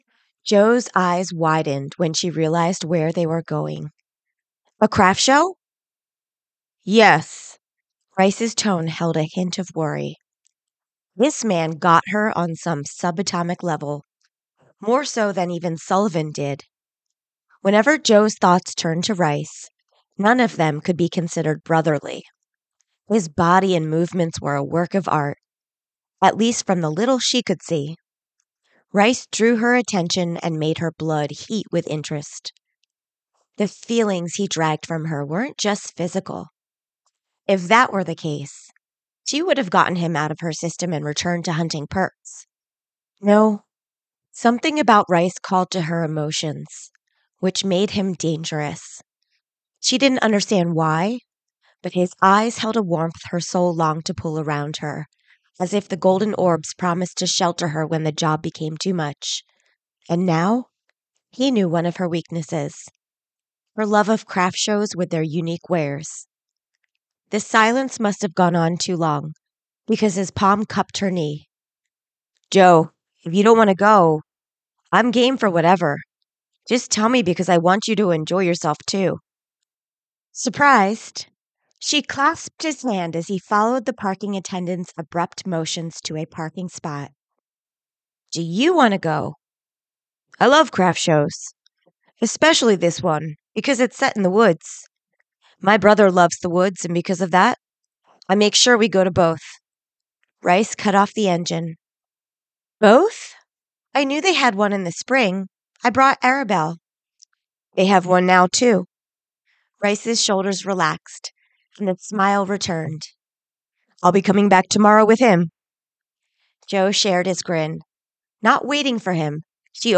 Gravedigger Audiobook – CeeRee Fields